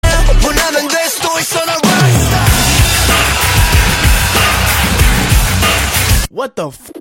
SK_guitar_fx_pick_slide